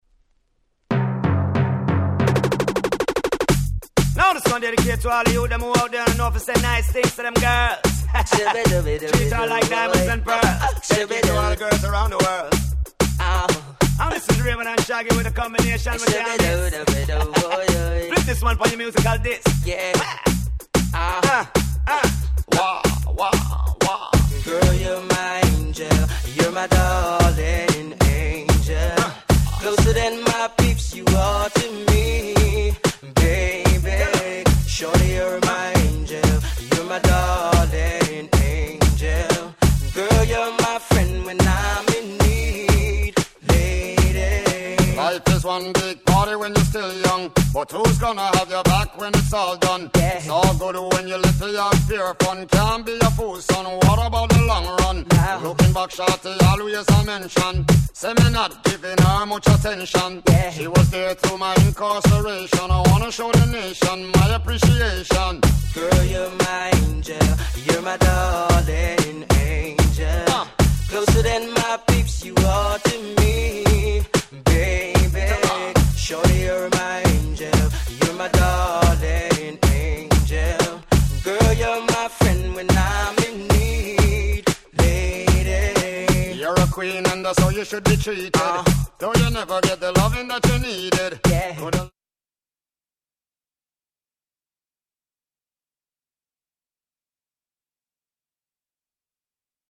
Reggaeの現場でも未だにPlayされ続けている、タイトル通り心が温かくなるナンバー！！
レゲエ